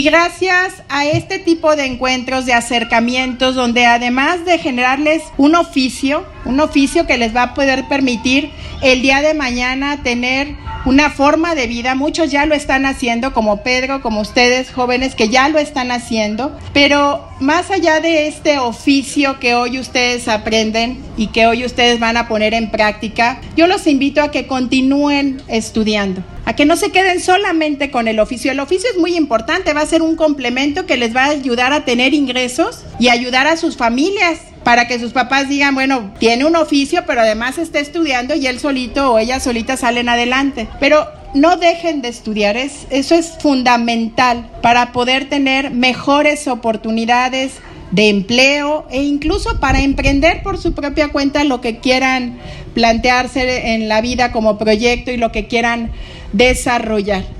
AudioBoletines
Lorena Alfaro García – Presidenta Municipal
Luis Ricardo Benavides Hernández – Secretario de Seguridad Ciudadana